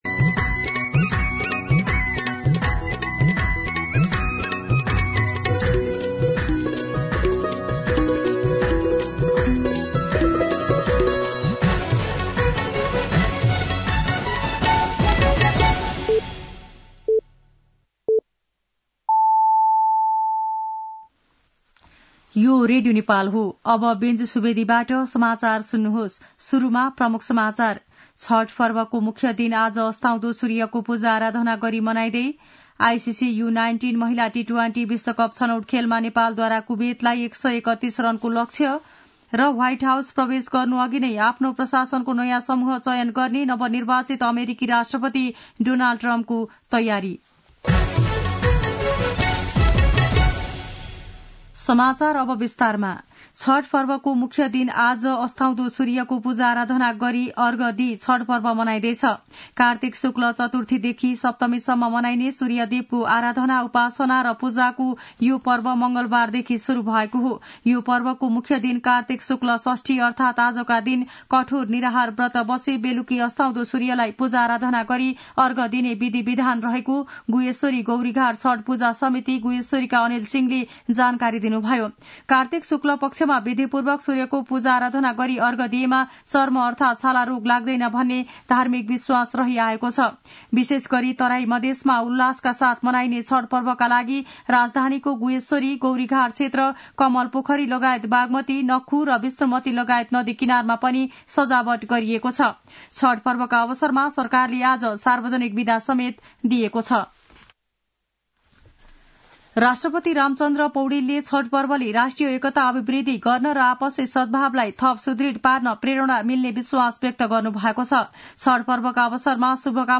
दिउँसो ३ बजेको नेपाली समाचार : २३ कार्तिक , २०८१